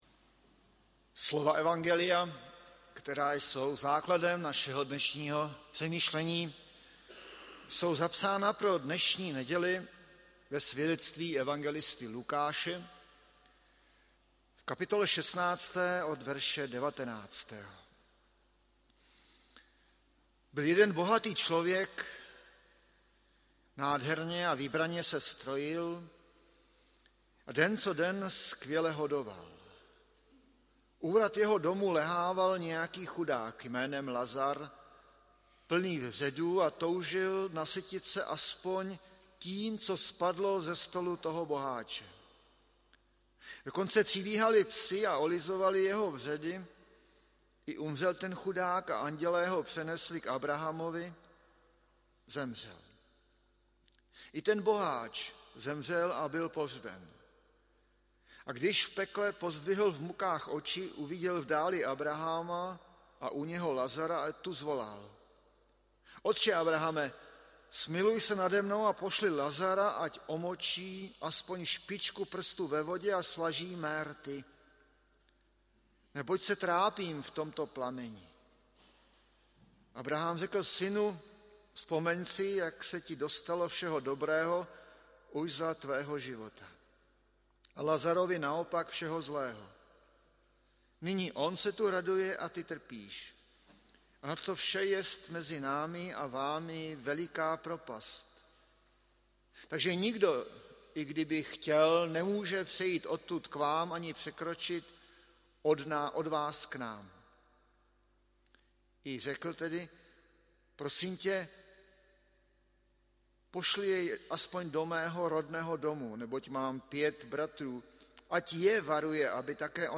15. neděle po sv. Trojici 25. září 2022 AD
audio kázání zde